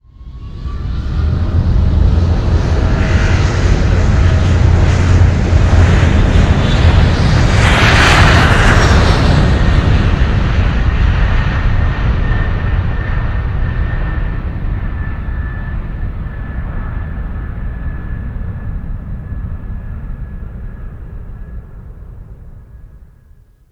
jetTakeoff.wav